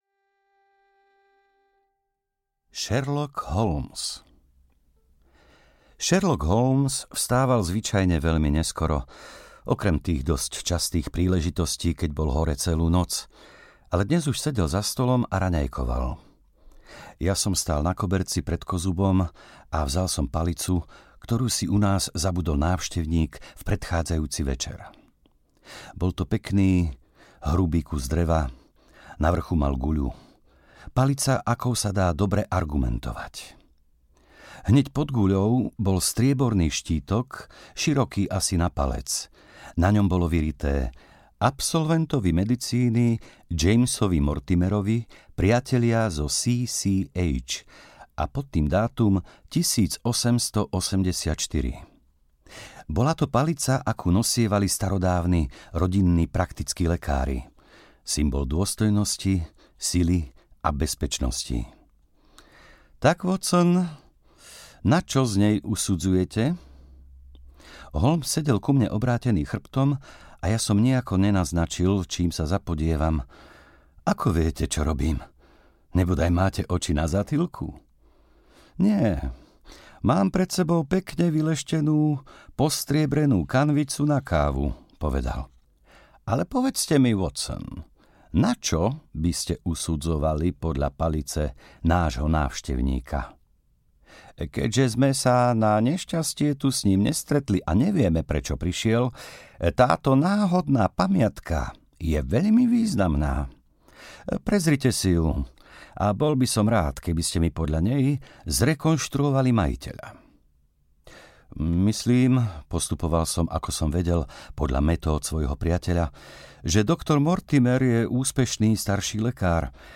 Pes baskervillský audiokniha
Ukázka z knihy
pes-baskervillsky-audiokniha-1